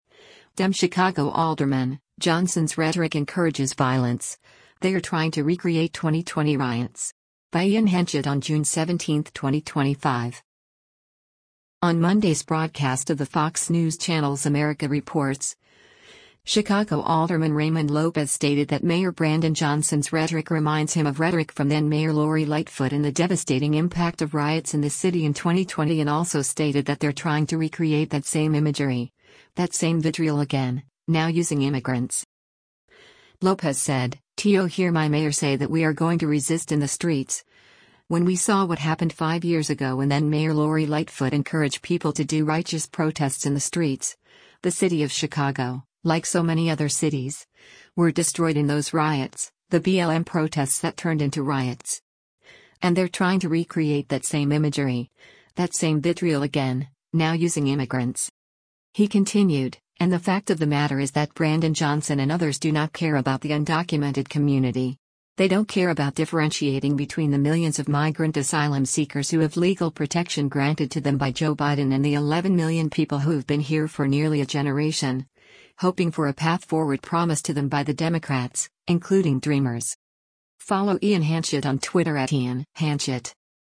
On Monday’s broadcast of the Fox News Channel’s “America Reports,” Chicago Alderman Raymond Lopez stated that Mayor Brandon Johnson’s rhetoric reminds him of rhetoric from then-Mayor Lori Lightfoot and the devastating impact of riots in the city in 2020 and also stated that “they’re trying to recreate that same imagery, that same vitriol again, now using immigrants.”